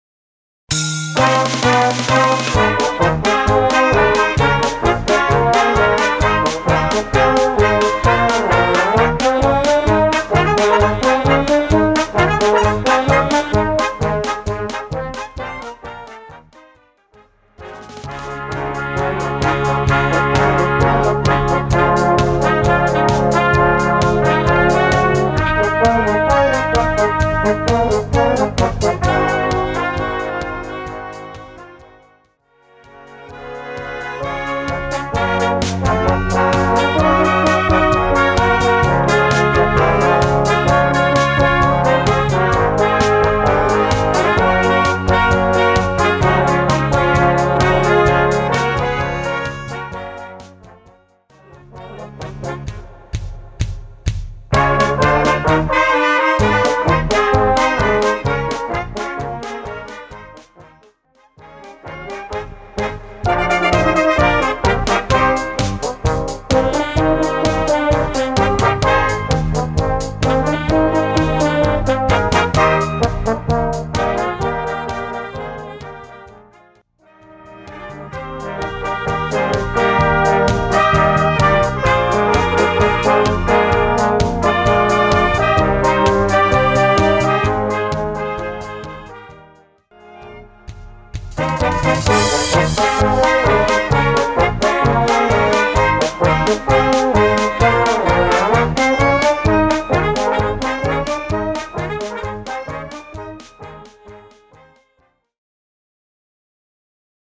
Gattung: Potpourris
Besetzung: Blasorchester